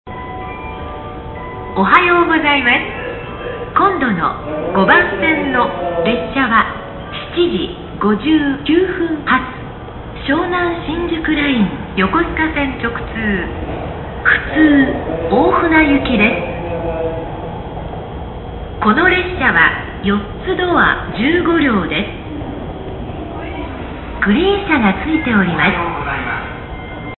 朝の次発放送朝の次発放送です。
おはようございますを先頭につけ、あとは通常通りの放送である。なお、この音声は東北貨物線のものです。ご了承ください。